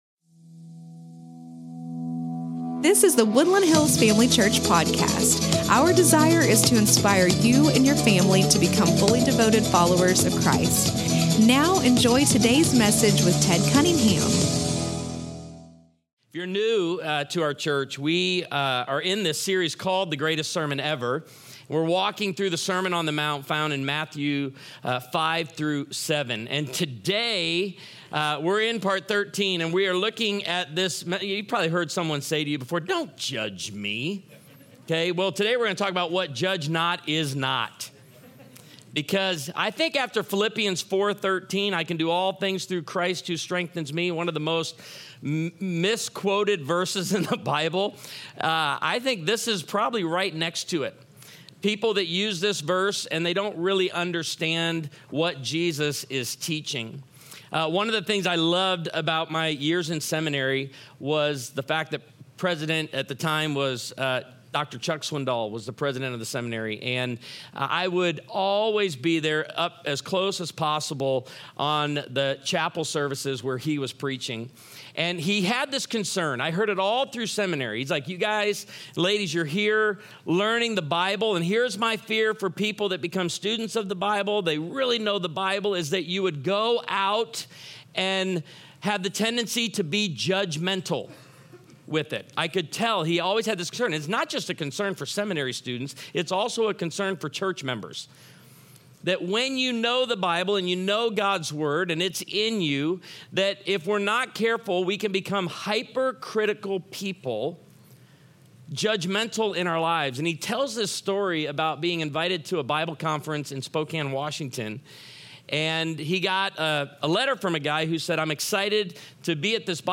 The Greatest Sermon Ever (Part 13) | Woodland Hills Family Church
The Greatest Sermon Ever (Part 13)